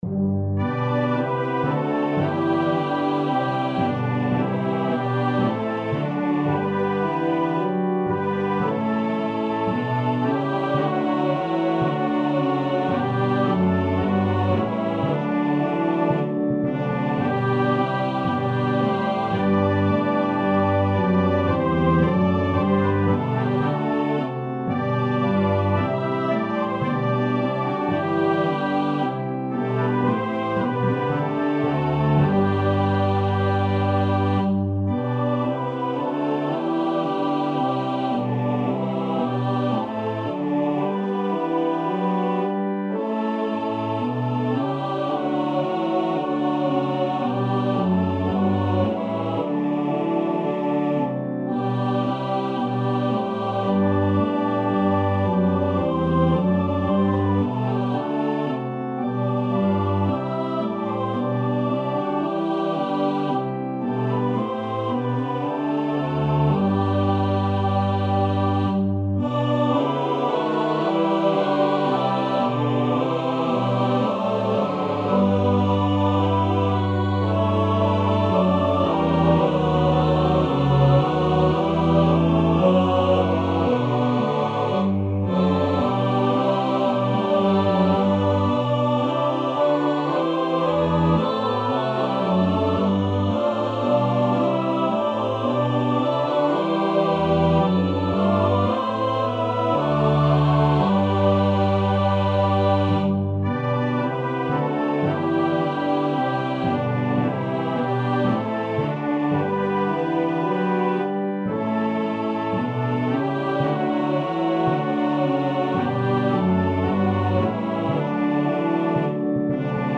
• Music Type: Choral
• Voicing: SATB
• Accompaniment: Brass Quartet, Congregation, Organ, Timpani